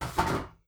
clamour1.wav